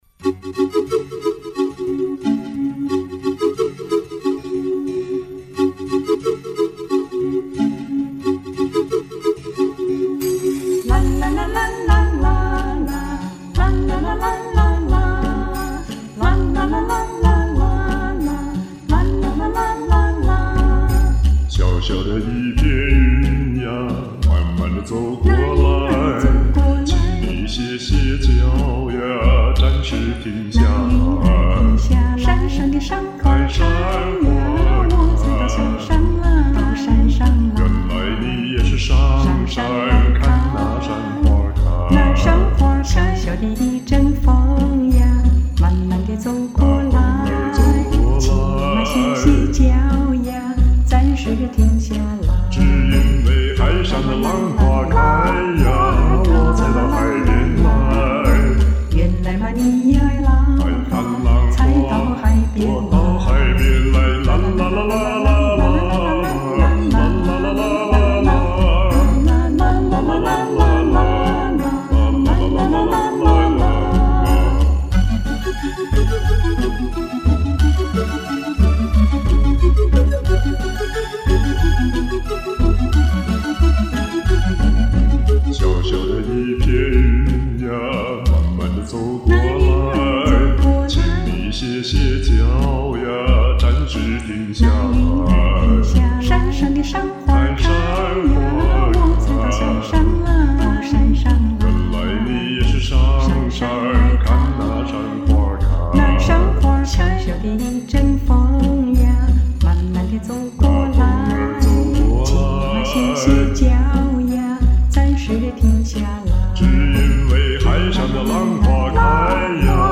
欢快深情的演唱， 叫好！
轻松惬意的歌声，令人无比愉悦！
辽阔 悠然~~~